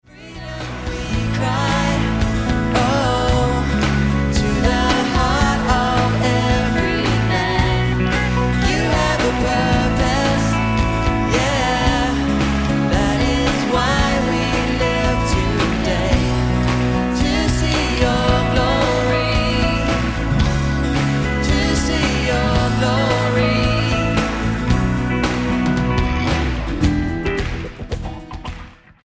STYLE: Pop
recorded live
captures the live worship at the event